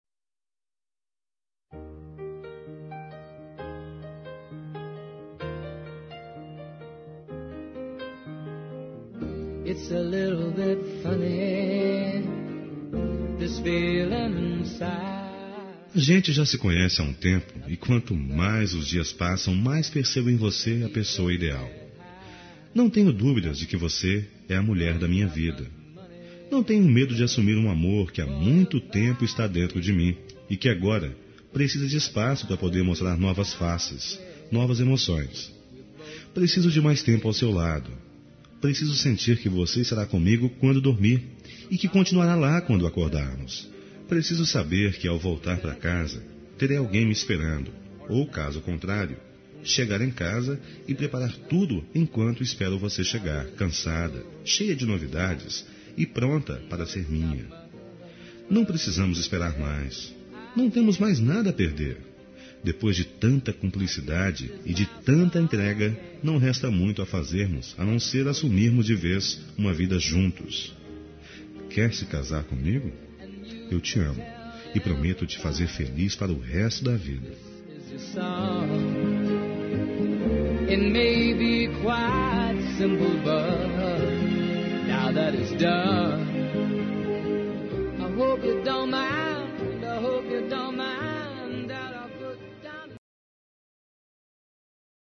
Telemensagem de Pedido – Voz Masculina – Cód: 041718 – Quer Casamento